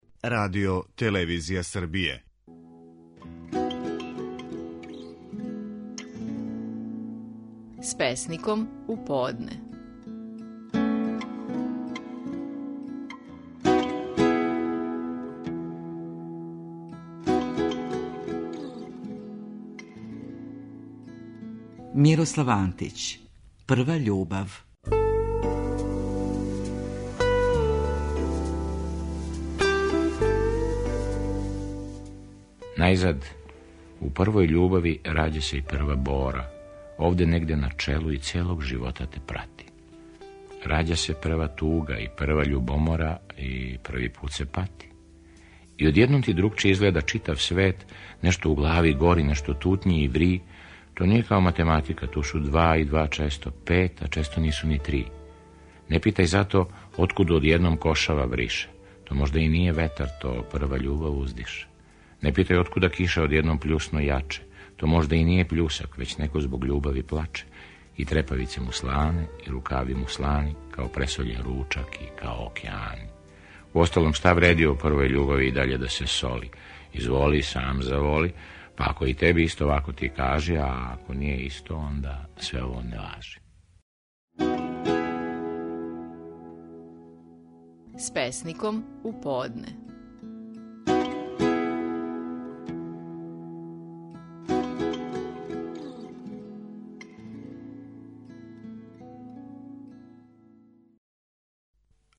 Наши најпознатији песници говоре своје стихове
Слушамо Мирослава Антића и песму „Прва љубав".